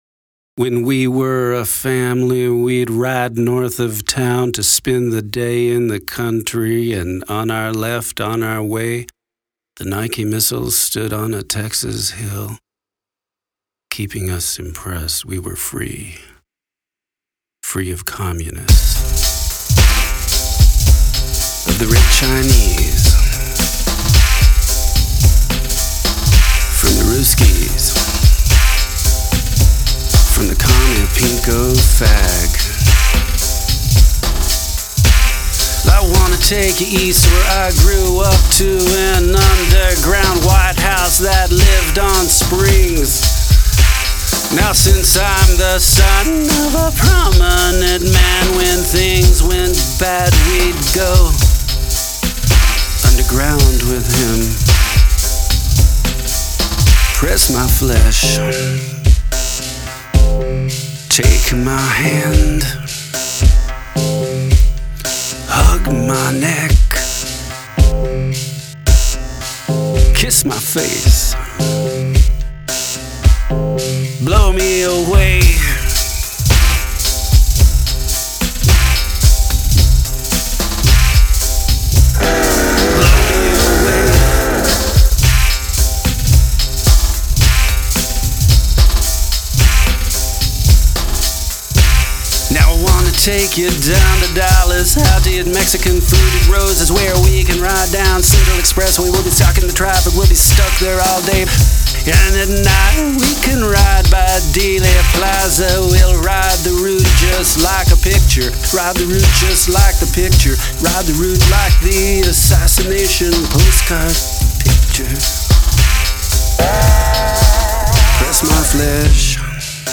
Steel guitar